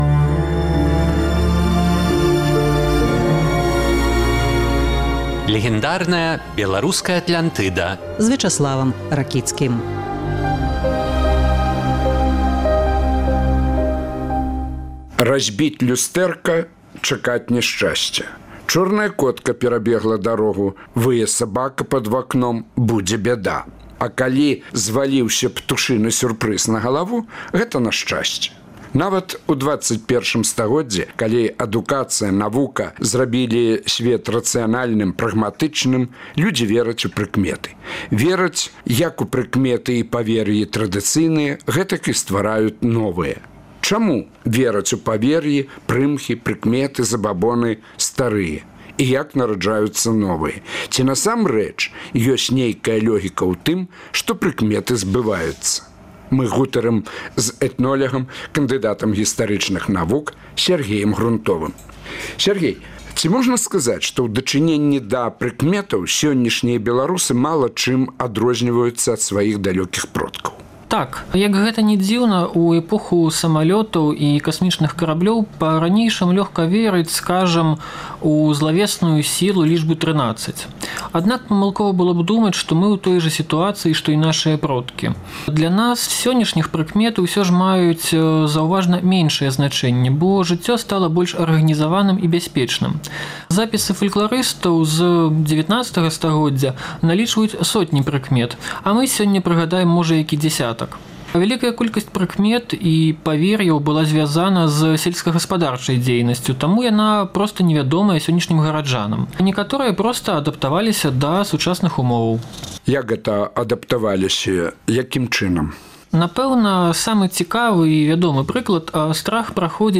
Нават у ХХI стагодзьдзі людзі вераць у прыкметы. Чаму вераць у забаьбны старыя і як нараджаюцца новыя? Гутарка